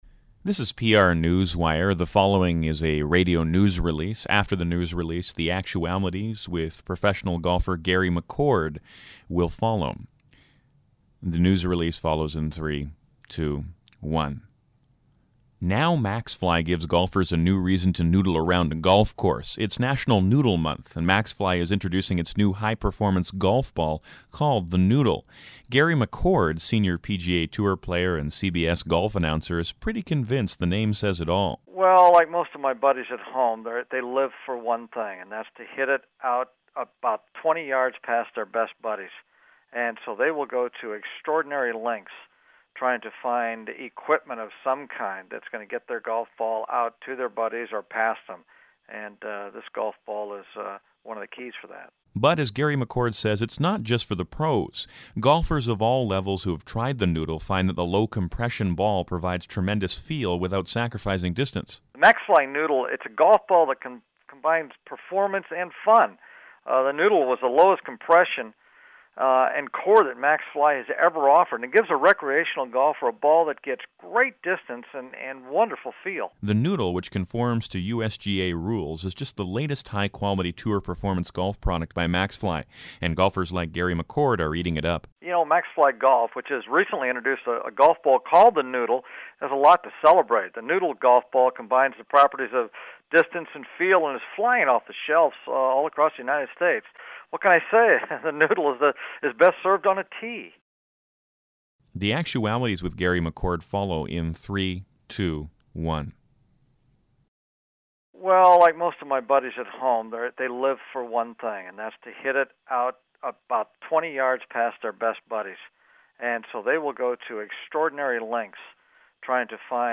* Soundbites: Gary McCord, popular Senior PGA Tour player and CBS golf announcer -- the official spokesman for The Noodle Golf ball.